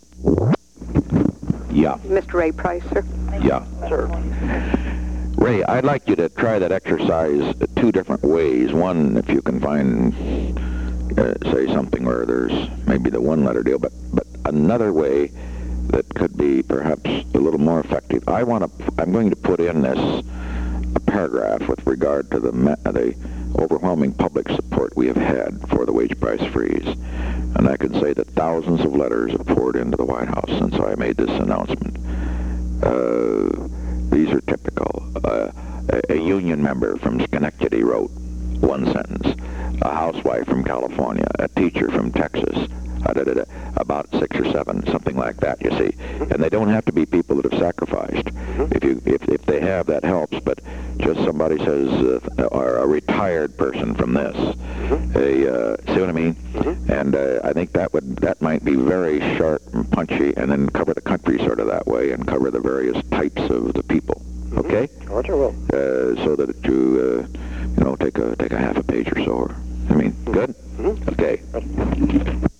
Recording Device: White House Telephone
On October 6, 1971, President Richard M. Nixon and Raymond K. Price, Jr. talked on the telephone from 11:46 am to 11:47 am.